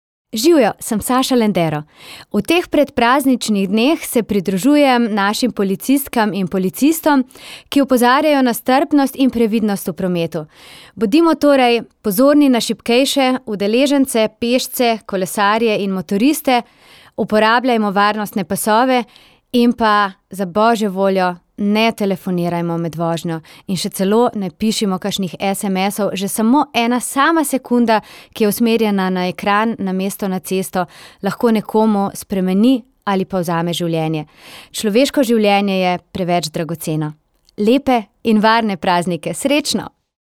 Na previdnost in strpnost v prometu med prazniki letos opozarja tudi pevka in voditeljica Saša Lendero.
Zvočni posnetek preventivne izjave Saše Lendero (mp3)